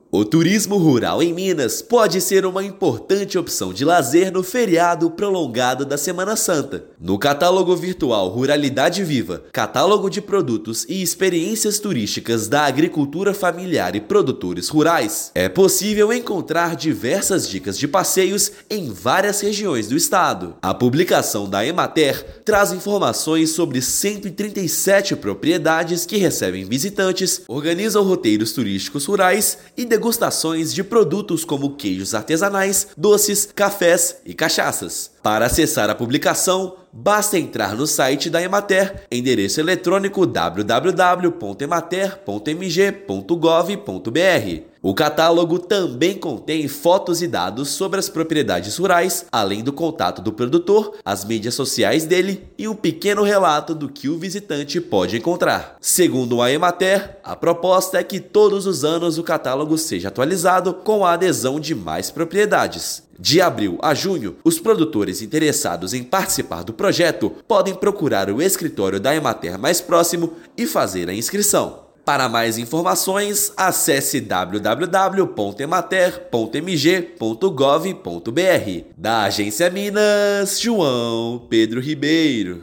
[RÁDIO] Turismo rural é uma boa opção de passeio no feriado
Catálogo Ruralidade Viva, disponível no site da Emater-MG, traz informações de 137 propriedades rurais que recebem visitantes. Ouça matéria de rádio.